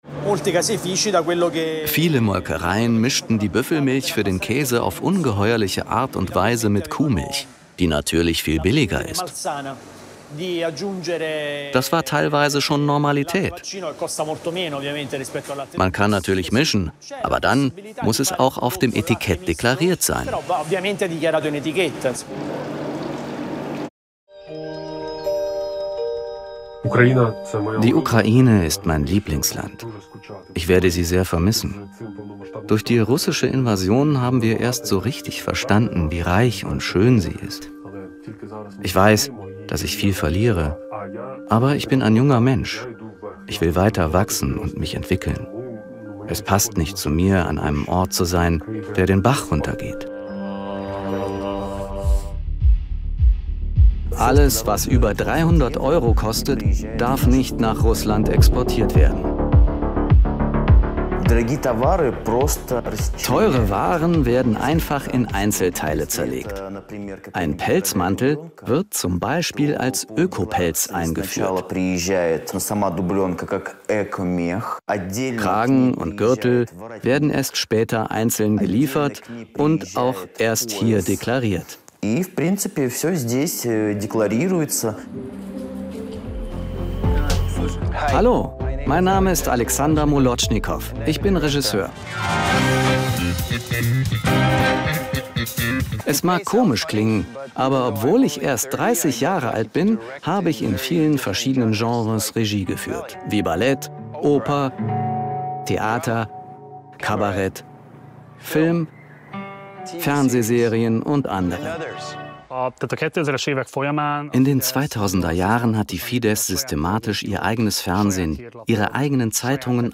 Voice Over – Over Voice – Arte Reportage – verschiedene Charaktere, Haltungen